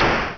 explosion1.wav